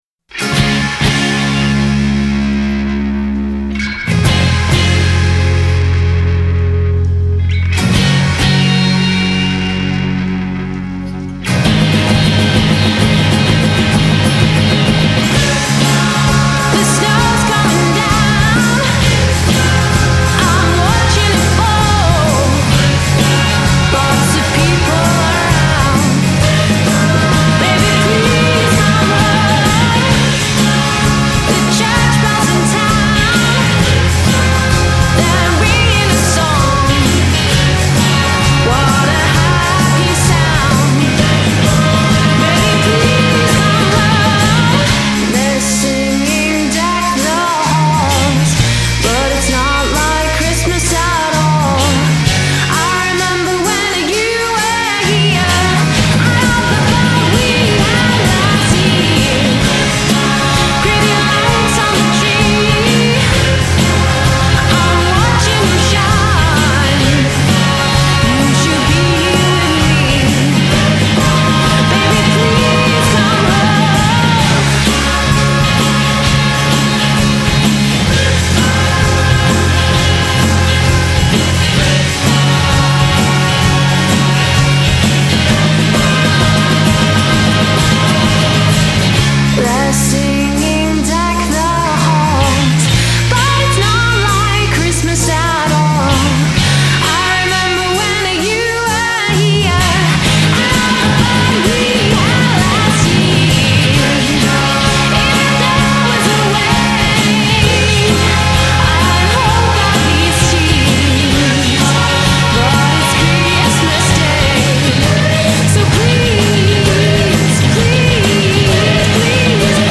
British folk-pop duo